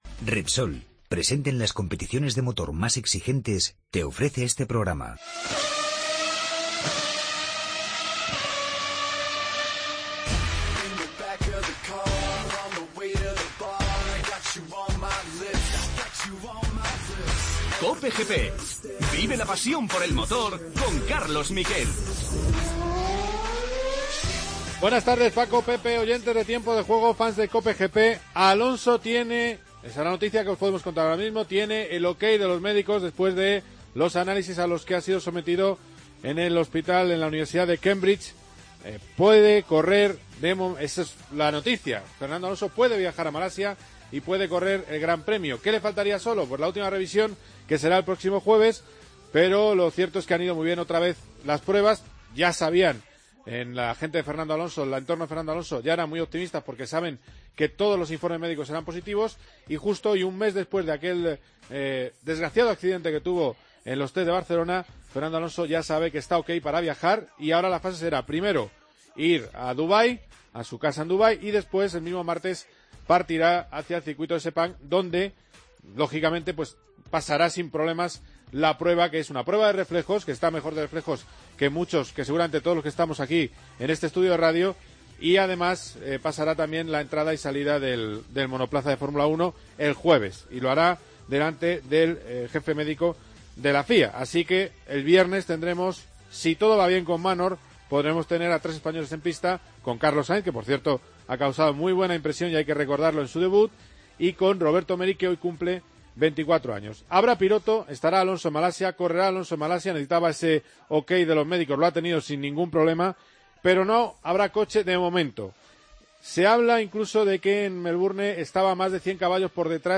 Noticia de última hora: Fernando Alonso pasa los test médicos de la FIA y correrá en Malasia. Previa del Mundial de motos que empieza el próximo domingo en Qatar. Entrevistas a Marc Márquez, Jorge Lorenzo y Tito Rabat.